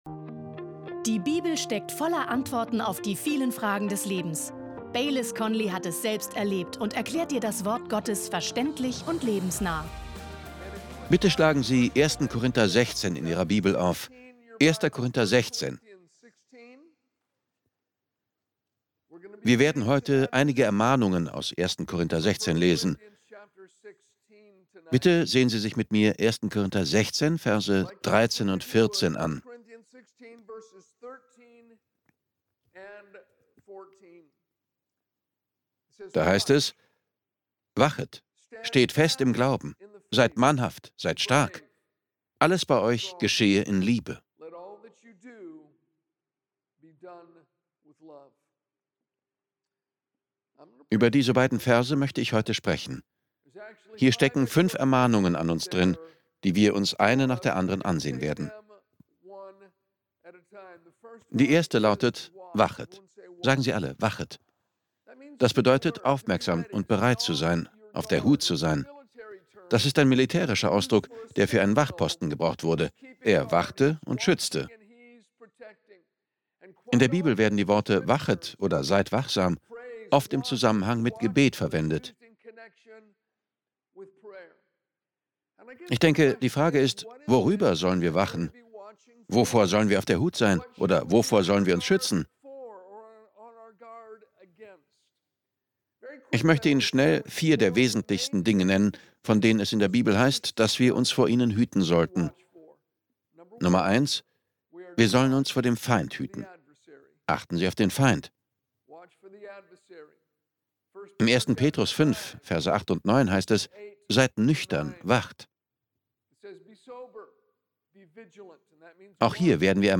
Schau dir diese Predigt an und lerne mehr und mehr im Sieg von Jesus Christus zu leben.